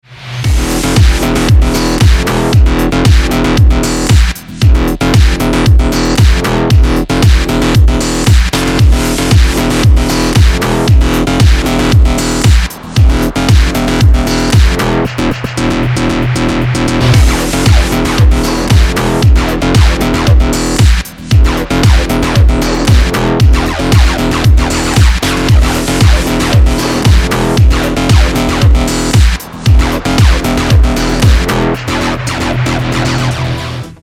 • Качество: 320, Stereo
ритмичные
Electronic
без слов
качающие
synthwave
Качающий synthwave из популярной игры